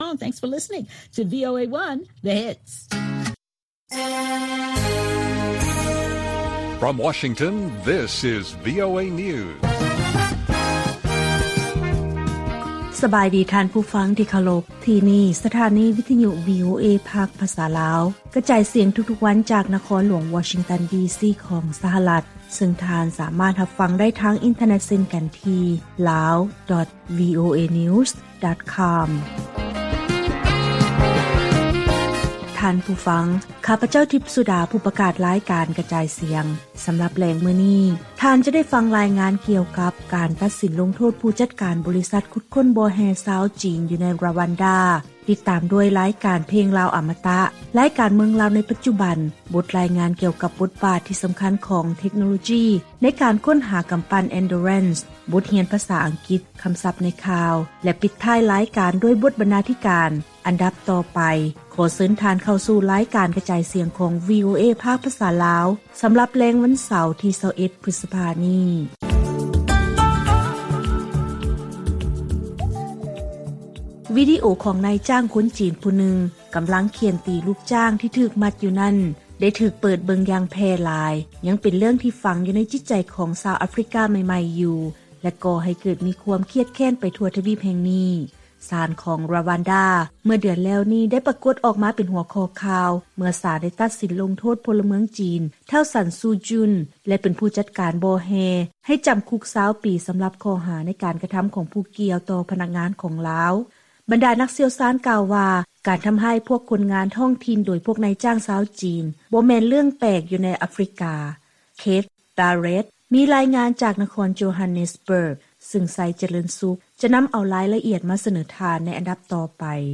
ວີໂອເອພາກພາສາລາວ ກະຈາຍສຽງທຸກໆວັນ ສຳລັບແລງມື້ນີ້ ເຮົາມີລາຍງານ: 1. ການລະເມີດສິດທິພວກຄົນງານອາຟຣິກາຂອງນາຍຈ້າງຈີນ 2. ເທັກໂນໂລຈີສາມາດຊ່ວຍໃນການຊອກຫາກຳປັ່ນ ແລະ 3. ນະຄອນຫຼວງວຽງຈັນຄາດວ່າ ຈະດຶງດູດເອົານັກທ່ອງທ່ຽວຕ່າງປະເທດ ໃຫ້ໄດ້ນຶ່ງລ້ານຄົນ ໃນປີ 2022.